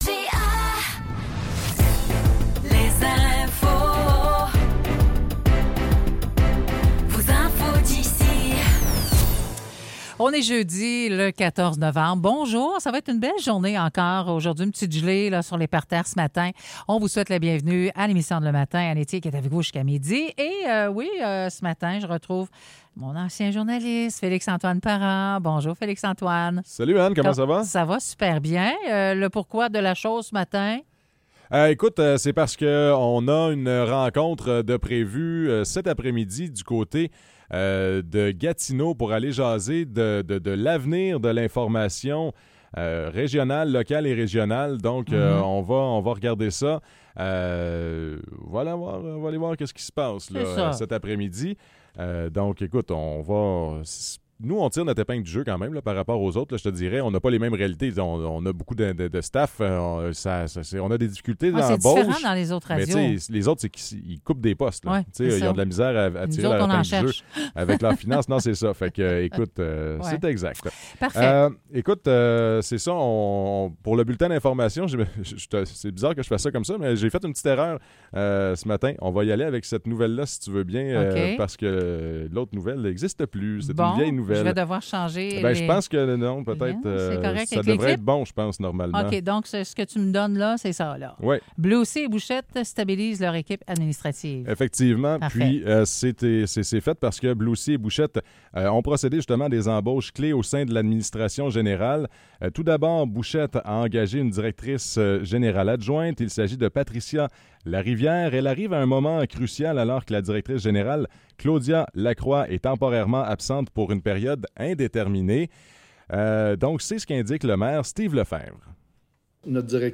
Nouvelles locales - 14 novembre 2024 - 9 h